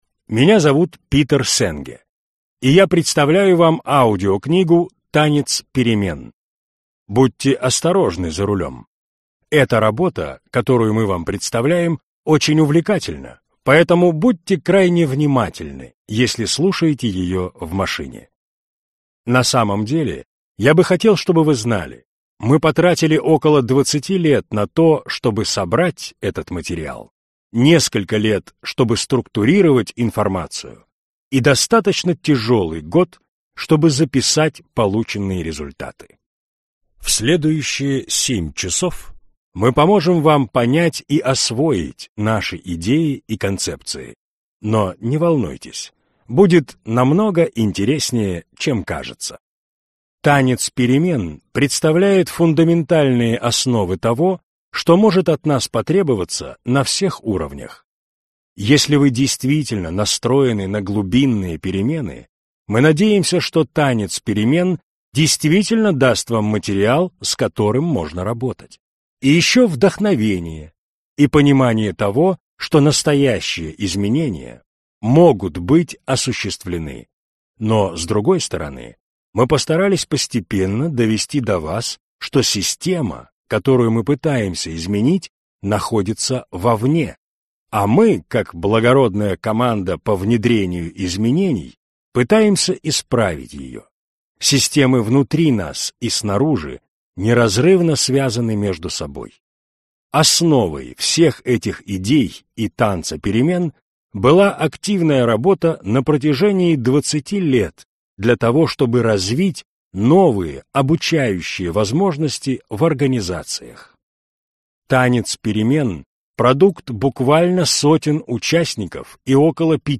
Аудиокнига Танец перемен: новые проблемы самообучающихся организаций | Библиотека аудиокниг